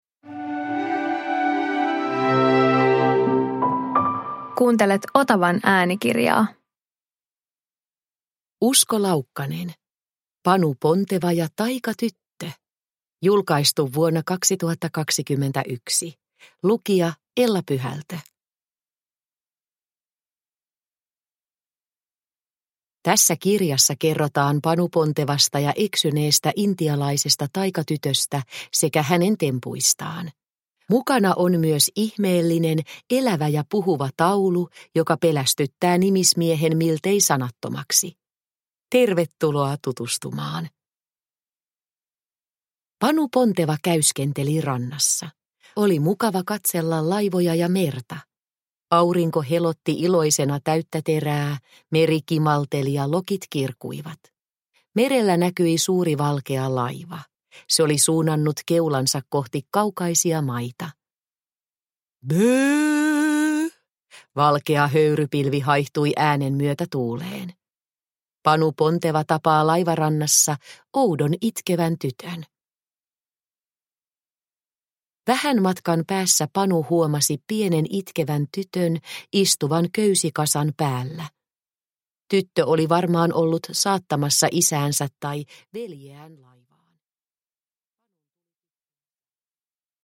Panu Ponteva ja taikatyttö – Ljudbok – Laddas ner